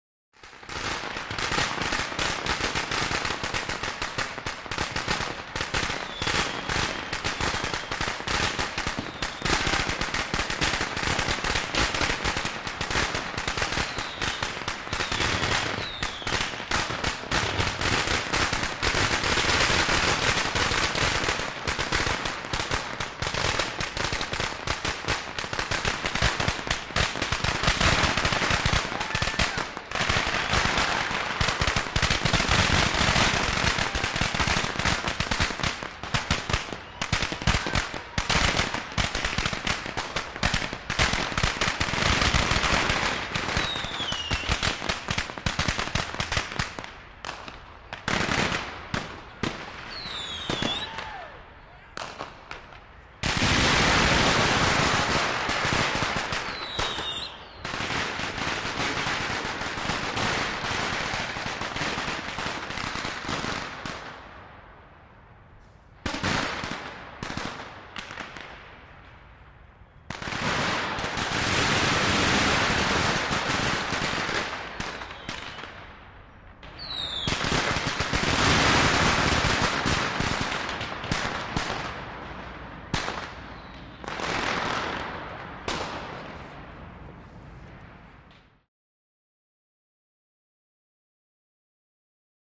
Fireworks; Almost Constant Close Fire Crackers Explode, Multiples. Some Medium To Distant Whistlers And Firecrackers With Bouncy Reverberation From Tall Buildings. Sparse Medium Yells.